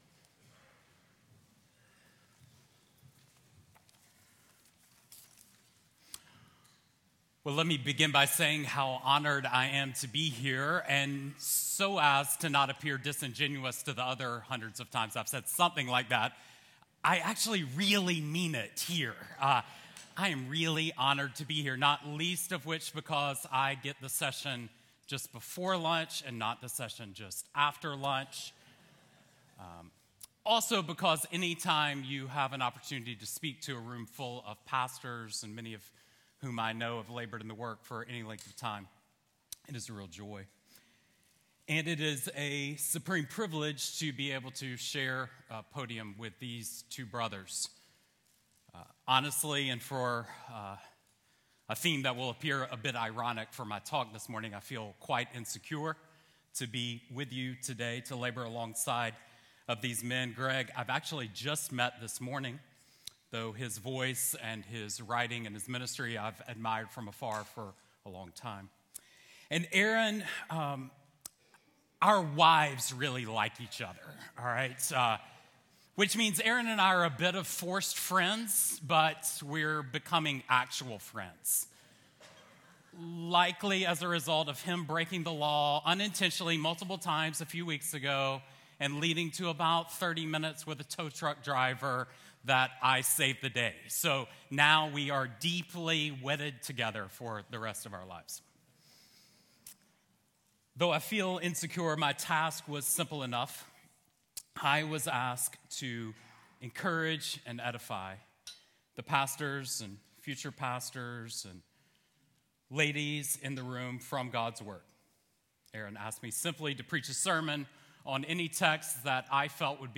Audio recorded at Feed My Sheep Conference 2025.